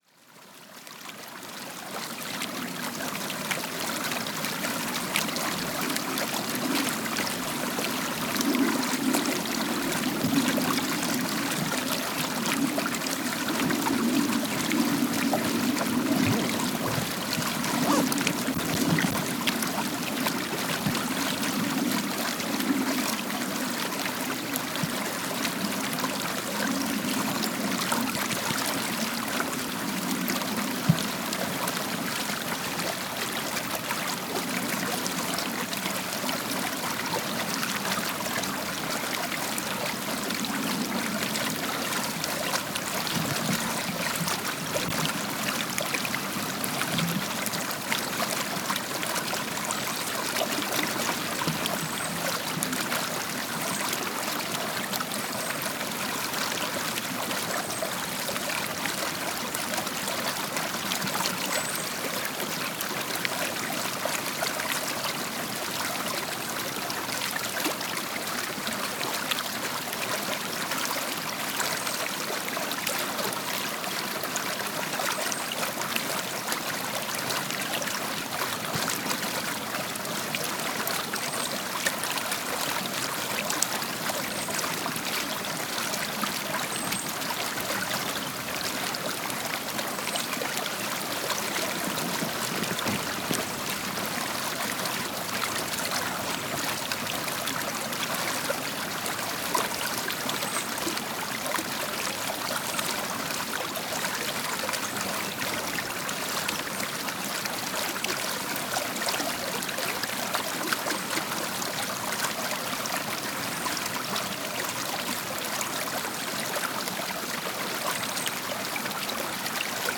Below is one of my inspirations, the natural sound of the stream and birdsong in winter, the soundtrack of the video at the bottom of this page. Allow it to lull you to sleep, or even incite your creative juices to sing a blessed noise to your creator, yourself, or no one at all!
StreamBirdSnowEDIT.mp3